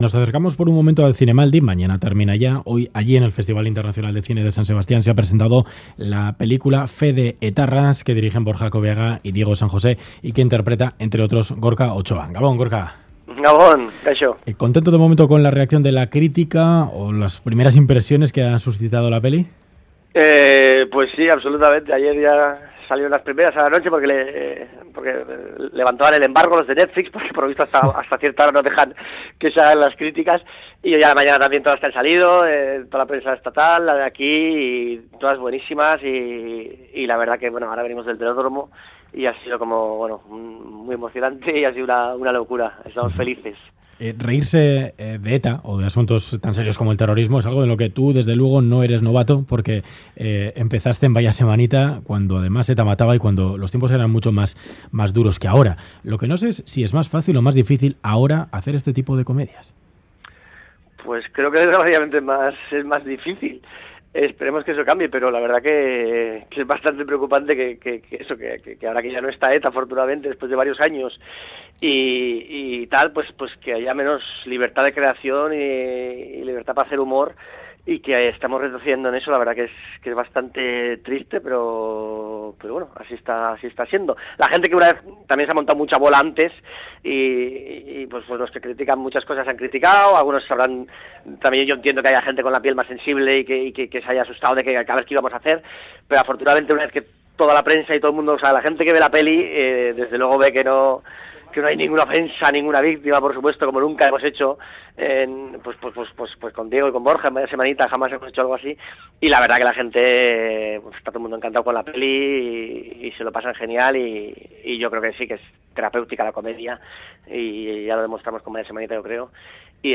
Audio: Entrevistamos en Ganbara al actor de “Fe de etarras” Gorka Otxoa horas después del estreno mundial de la película en el Zinemaldia.